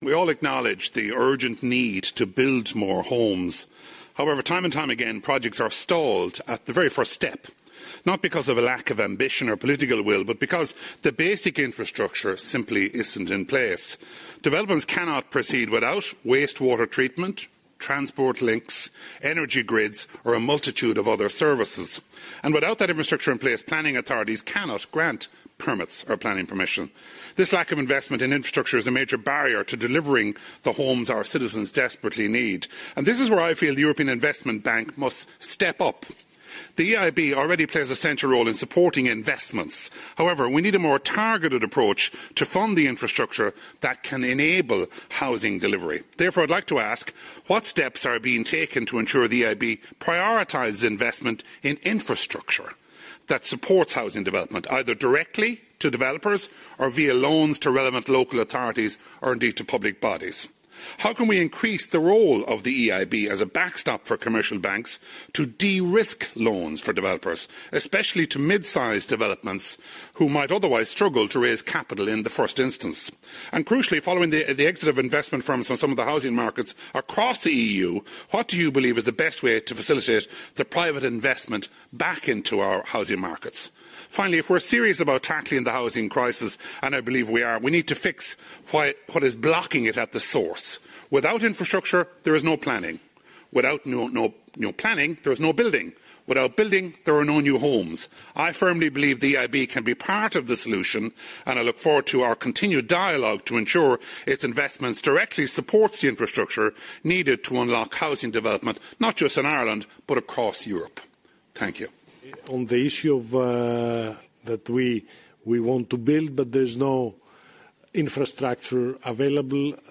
He addressed Mr Ioannis Tsakiris, Vice President of the European Investment Bank in Strasbourg:
mep-mullooly-vice-chair-housing-committee-questioning-the-eib-vice-president-tsakiris.mp3